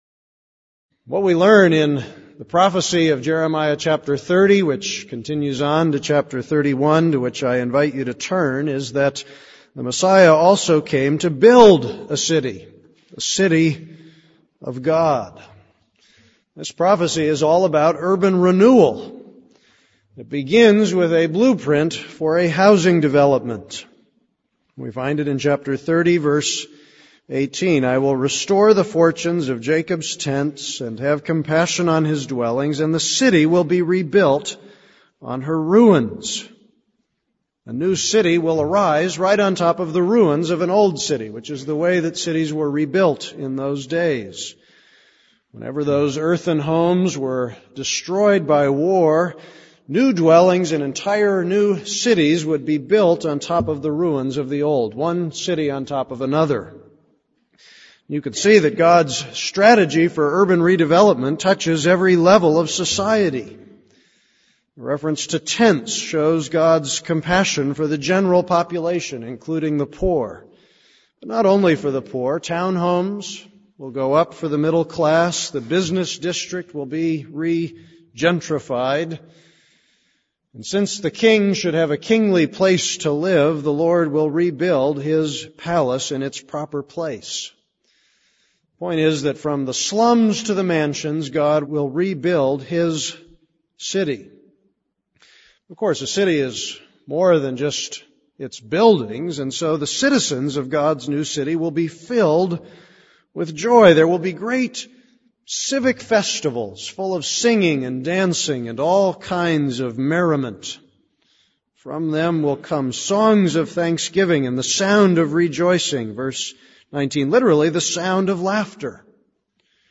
This is a sermon on Jeremiah 30:18; 21.